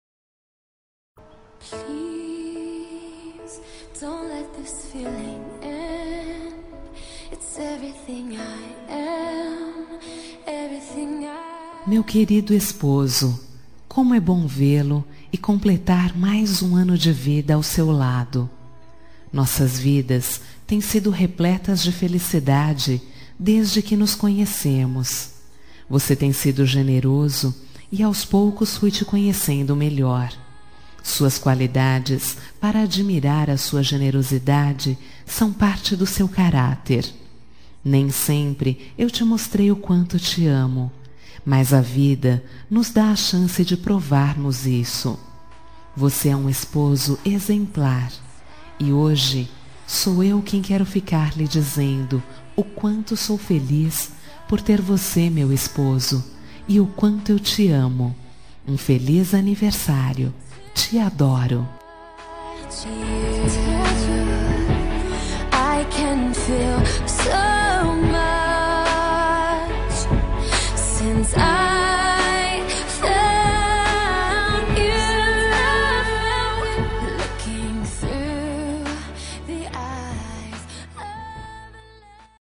Telemensagem de Aniversário de Marido – Voz Feminina – Cód: 1145